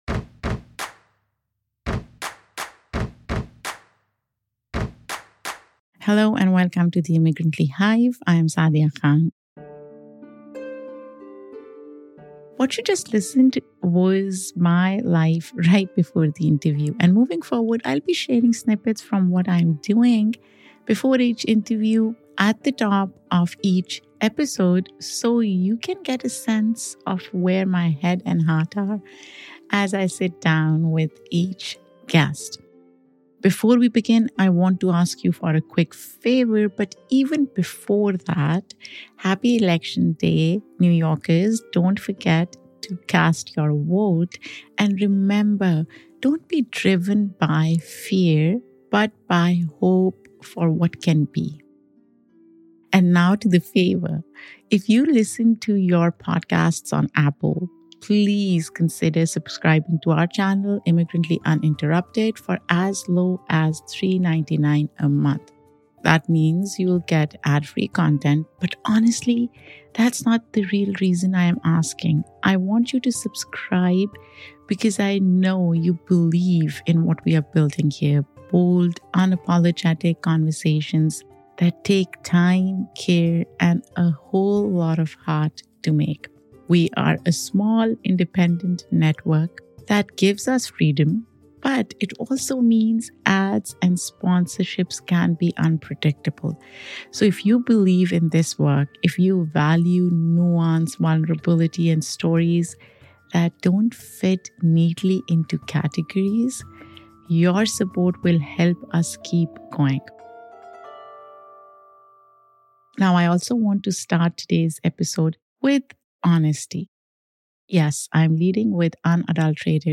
In this wide-ranging conversation